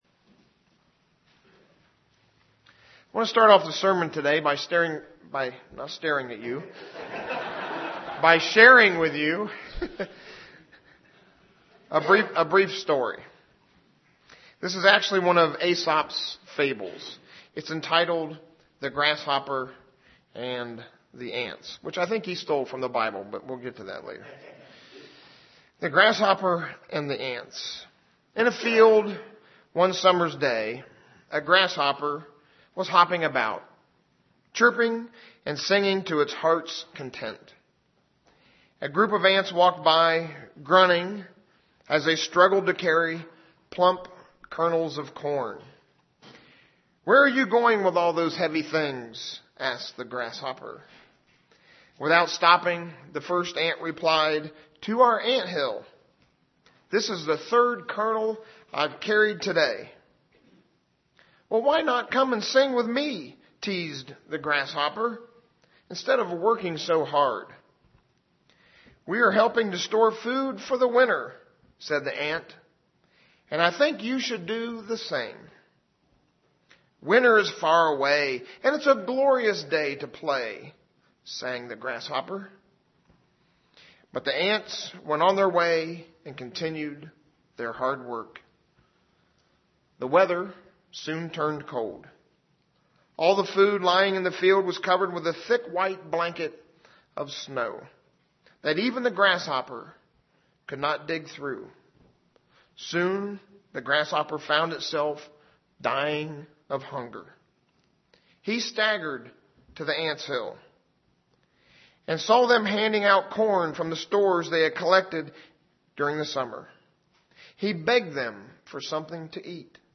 Given in Ft. Wayne, IN
UCG Sermon Studying the bible?